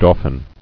[dau·phin]